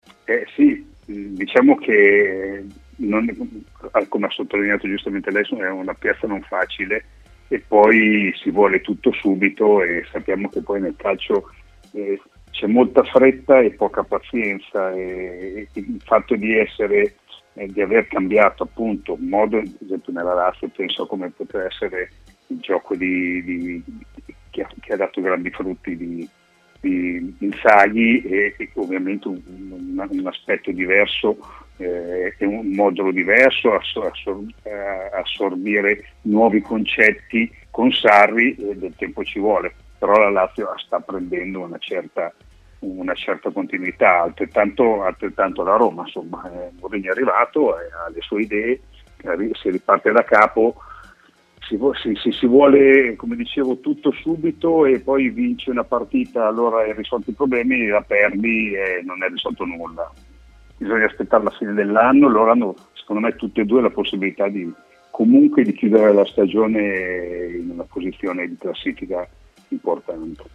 è intervenuto in diretta a Stadio Aperto, trasmissione di TMW Radio su alcuni temi del campionato italiano. Tra questi anche le prestazioni della Lazio che ultimamente sono frutto di una buona applicazione in campo.